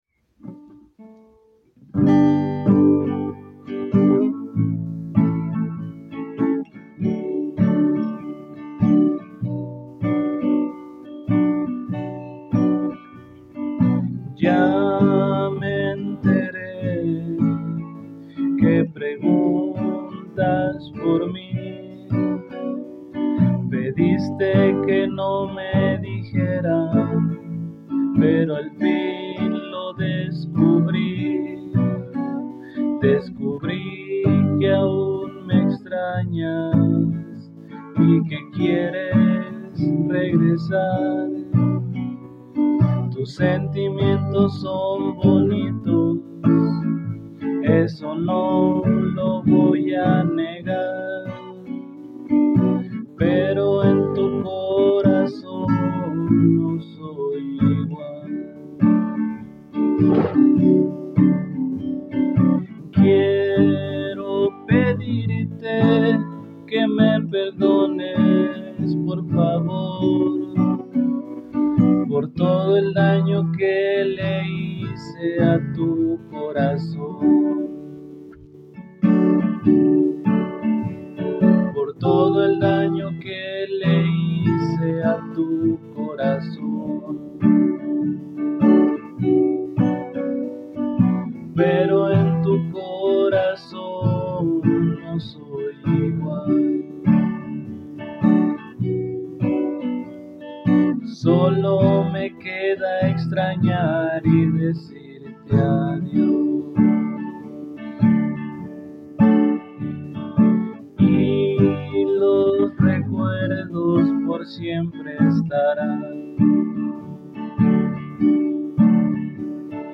disculpen el mal sonido